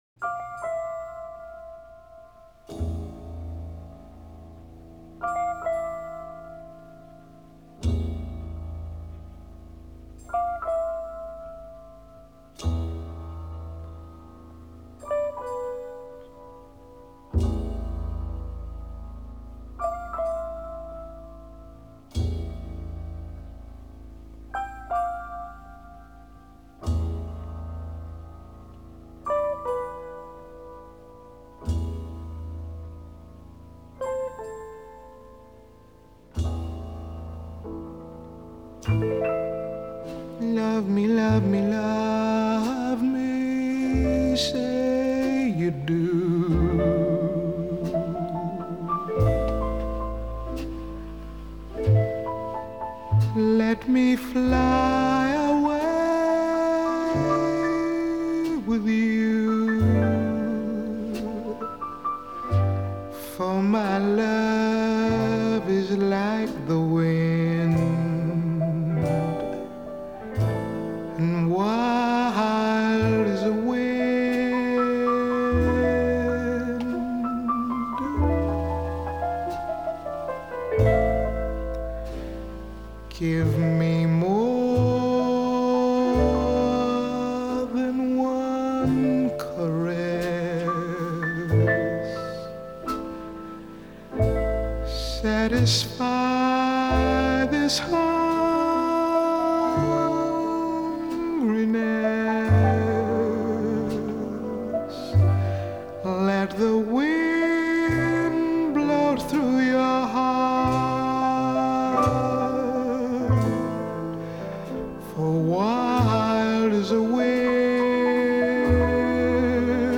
Джаз